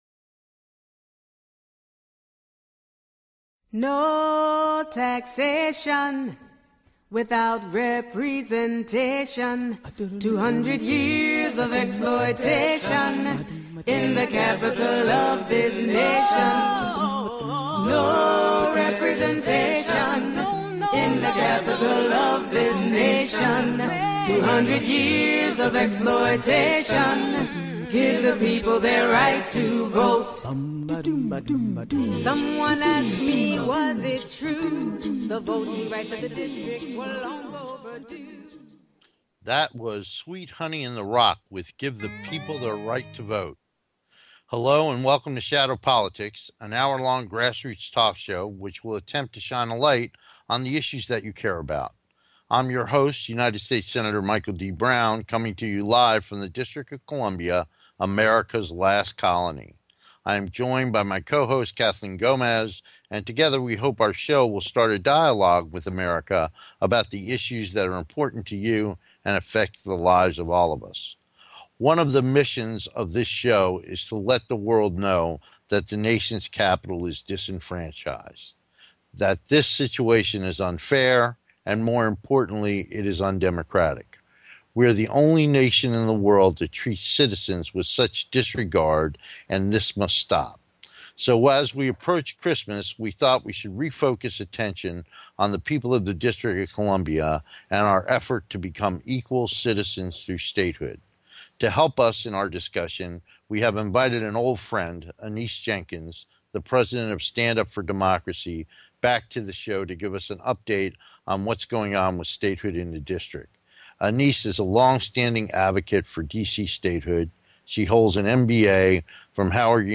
Shadow Politics is a grass roots talk show giving a voice to the voiceless.